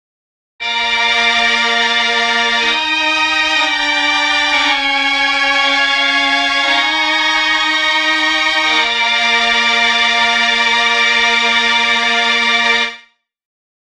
02-Chamberlin Strings
02-Chamberlin-Strings.mp3